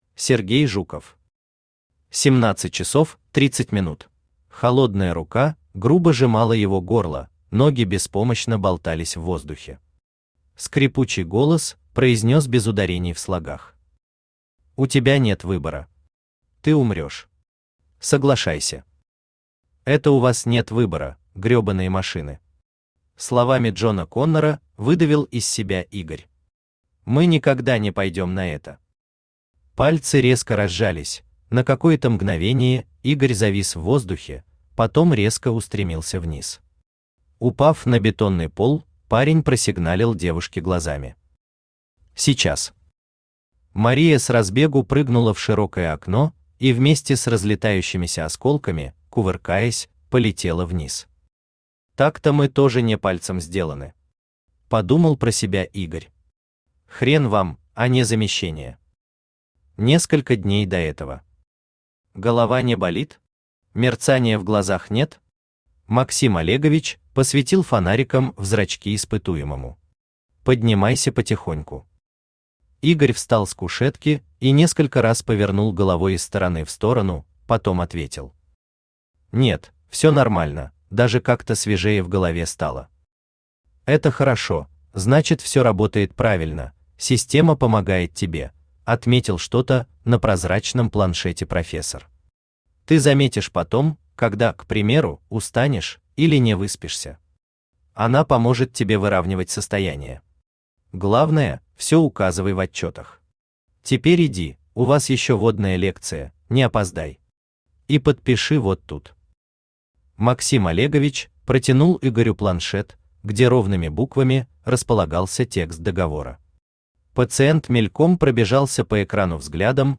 Aудиокнига 17:30 Автор Сергей Жуков Читает аудиокнигу Авточтец ЛитРес. Прослушать и бесплатно скачать фрагмент аудиокниги